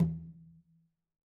5-conga.wav